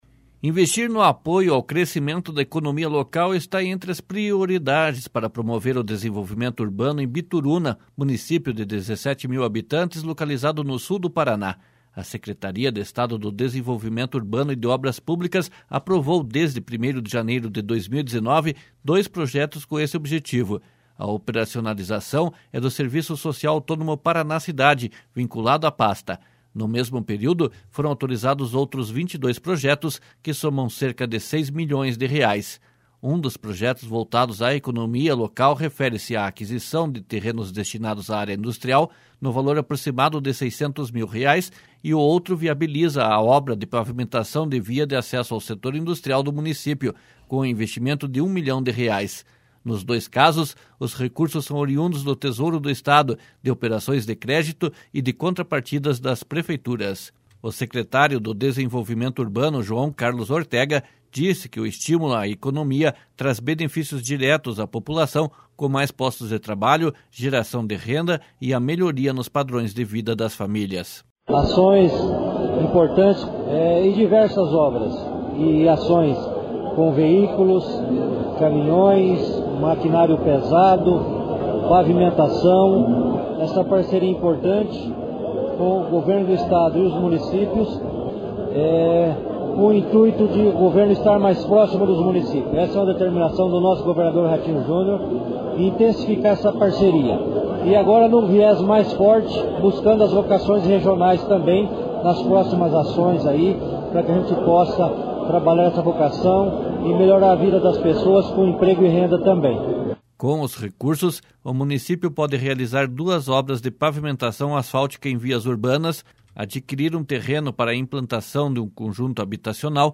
O secretário do Desenvolvimento Urbano, João Carlos Ortega disse que o estímulo à economia traz benefícios diretos à população, com mais postos de trabalho, geração de renda e a melhoria nos padrões de vida das famílias.